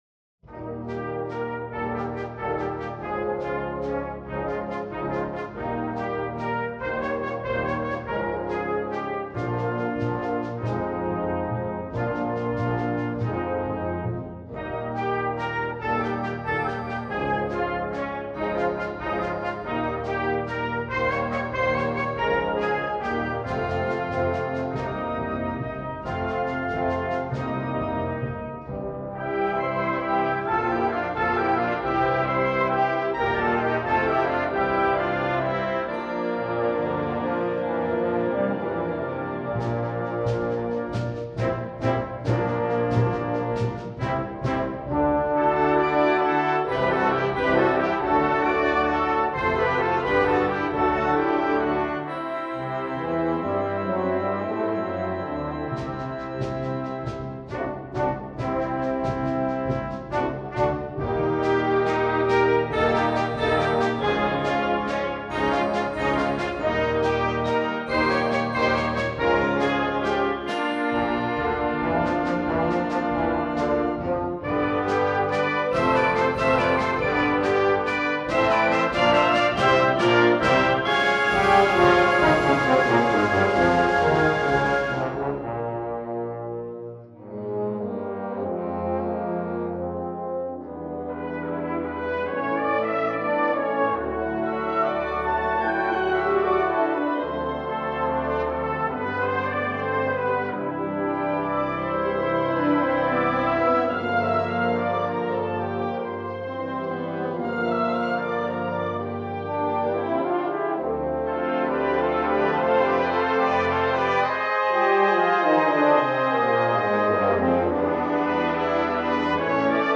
jazz, instructional